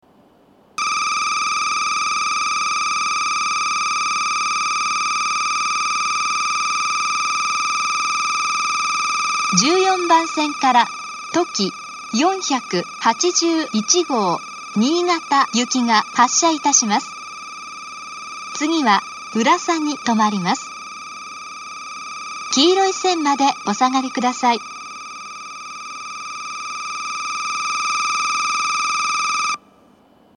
１４番線発車ベル とき４８１号新潟行の放送です。